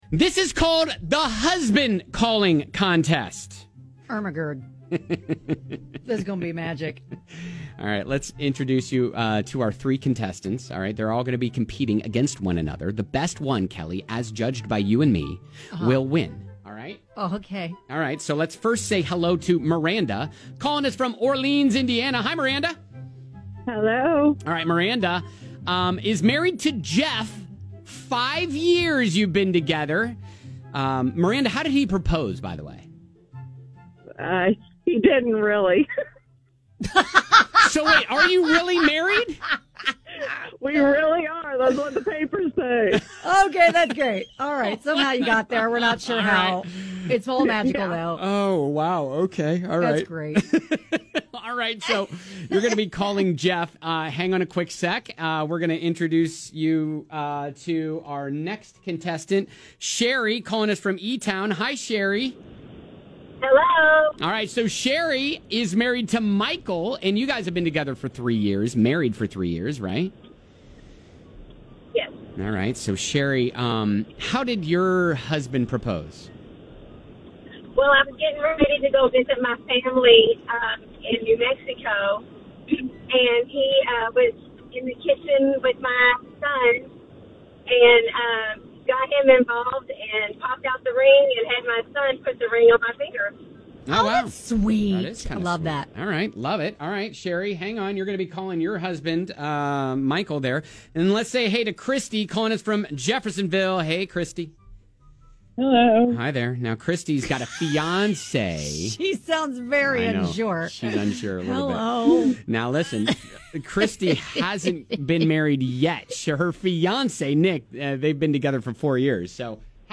We're giving away tickets and ride passes for the Kentucky State Fair, so we thought it would be fun to have listeners "call" their husbands!